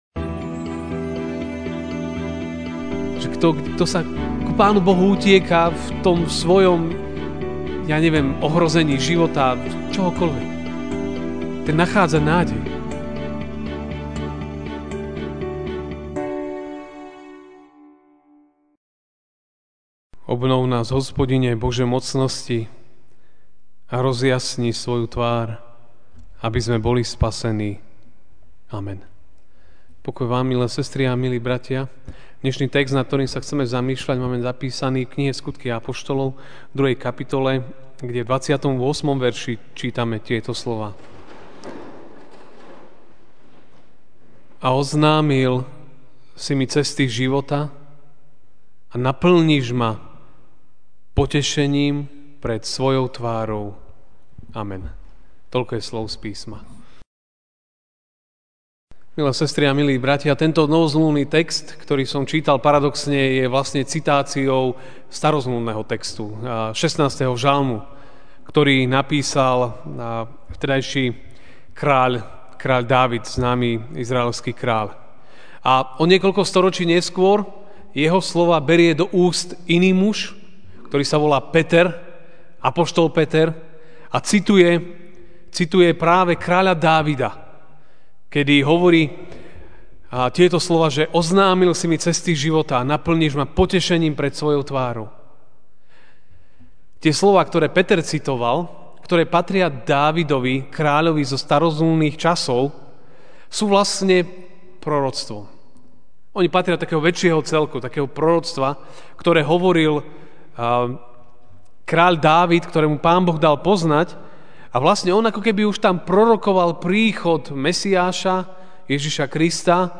MP3 SUBSCRIBE on iTunes(Podcast) Notes Sermons in this Series Večerná kázeň: Utekaj, skry sa, zavolaj!